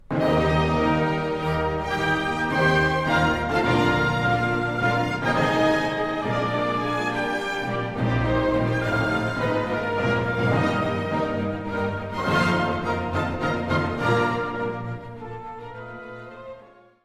melodie en harmonie / harmonisch ritme.